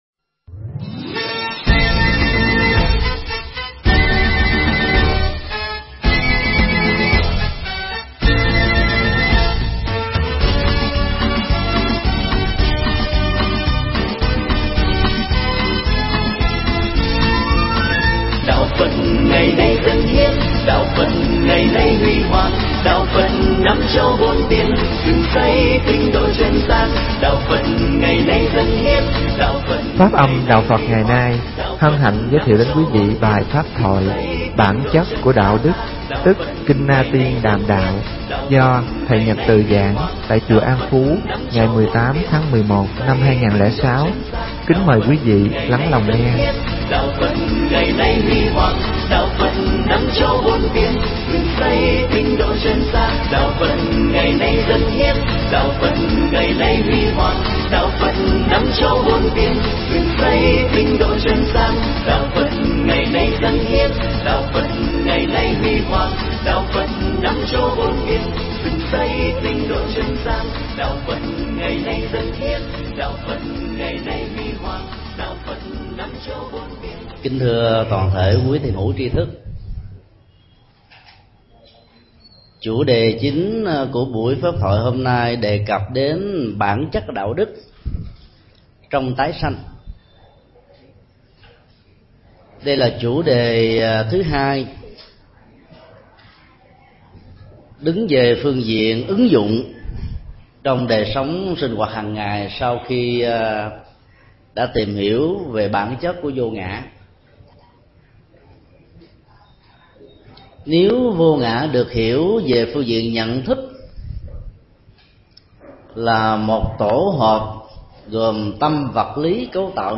Nghe mp3 Pháp thoại Bản Chất Của Đạo Đức do thầy Thích Nhật Từ giảng tại Chùa An Phú, ngày 18 tháng 11 năm 2006.